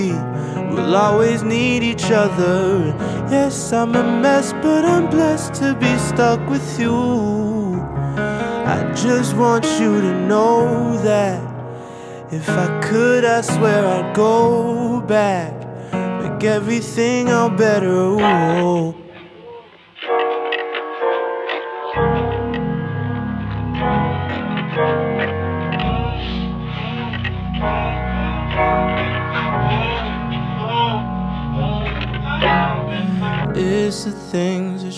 Жанр: R&B / Альтернатива / Соул